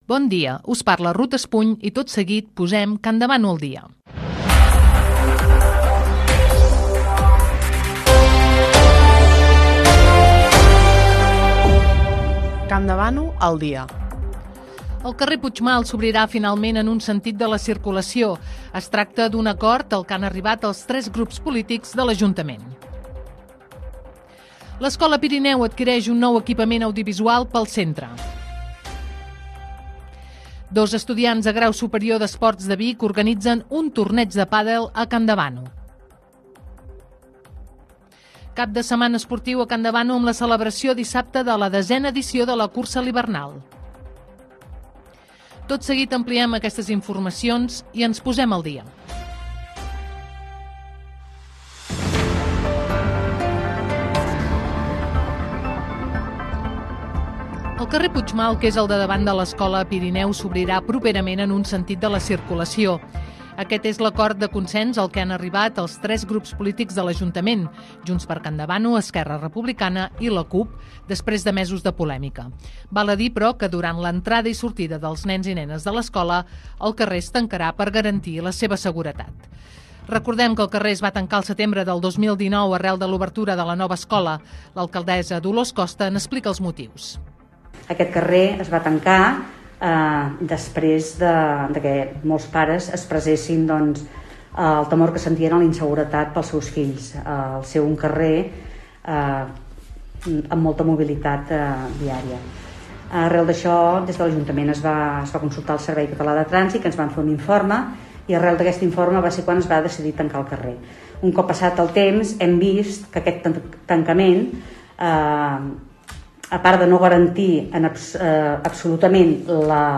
Careta i sumari de continguts. El carrer Puigmal s'obrirà en un únic sentit de circulació.
Informatiu